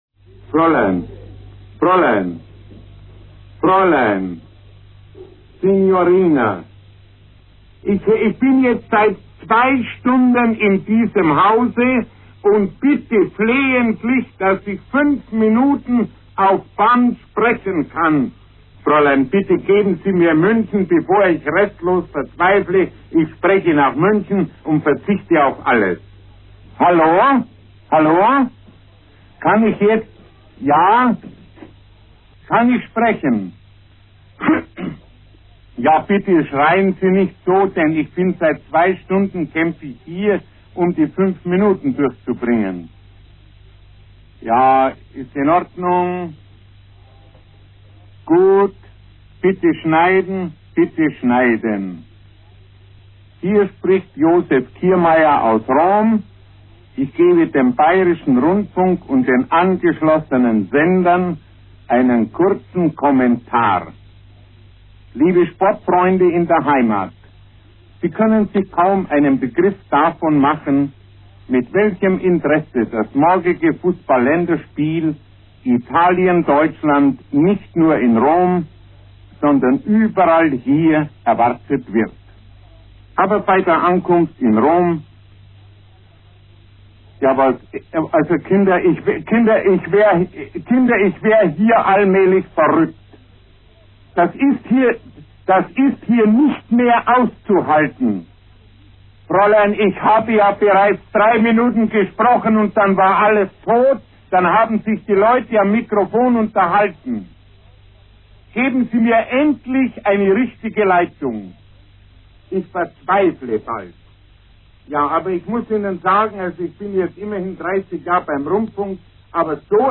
Bei einer Übertragung aus Italien klappt gar nichts
(Historischer Clip aus dem BR-Archiv).